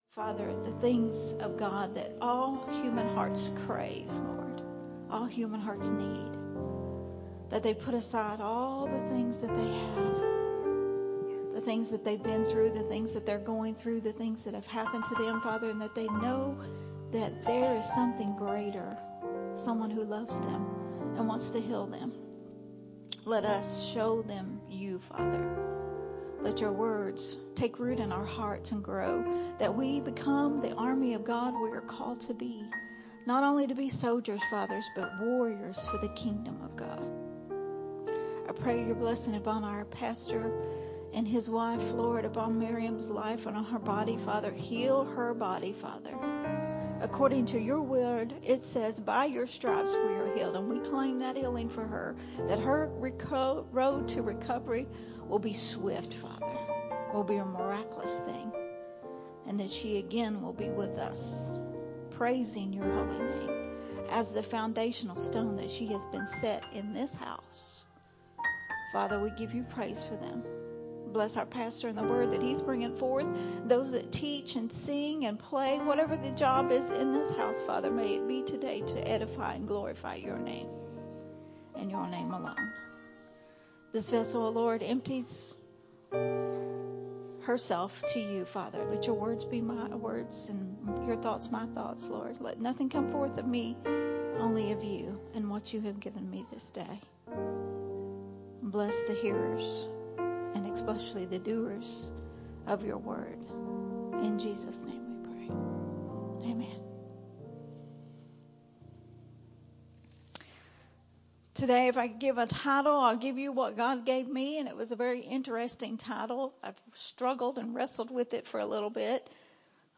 recorded at Unity Worship Center on February 26th, 2023.